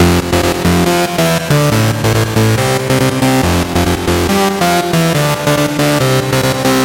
紧张气氛的升温
标签： 140 bpm Cinematic Loops Synth Loops 1.15 MB wav Key : Unknown
声道立体声